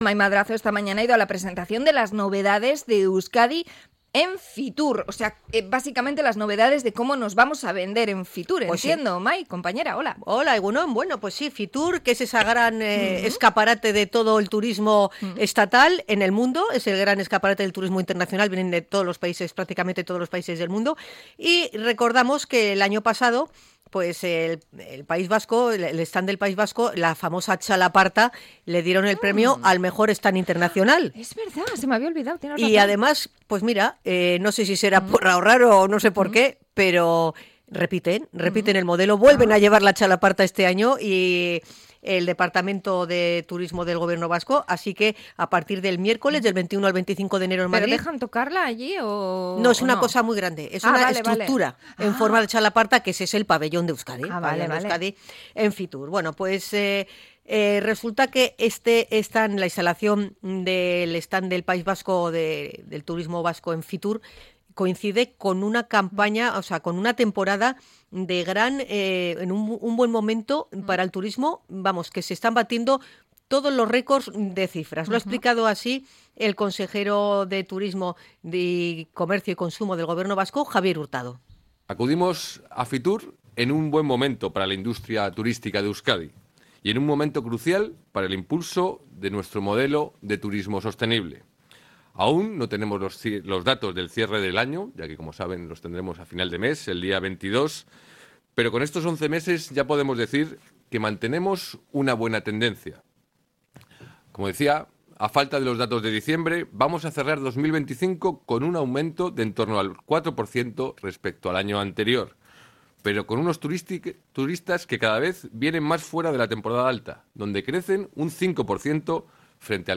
Crónica de la presentación del stand de Euskadi para Fitur